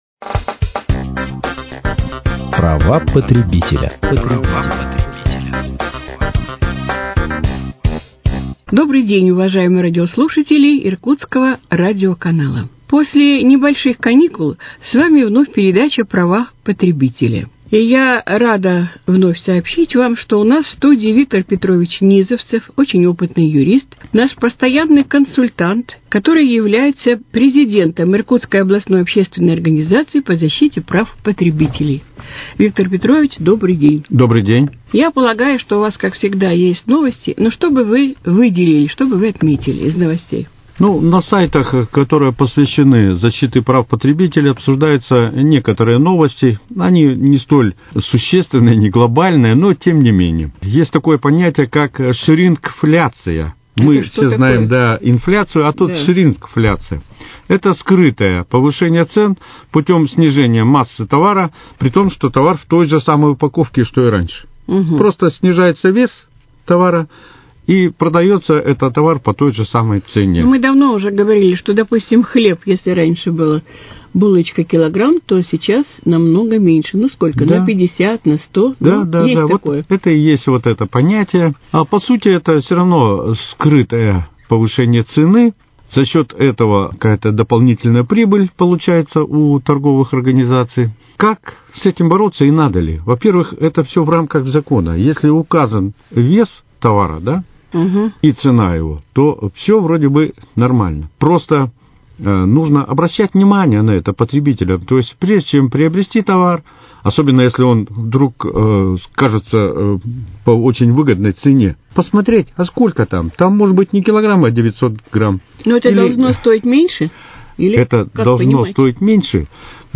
Без категории Права потребителя: О запрете мелкого шрифта, шринкфляции и других новостях 0:00 0:00 0:00 0:00 Без категории Права потребителя: О запрете мелкого шрифта, шринкфляции и других новостях Передача «Права потребителя». Ведущая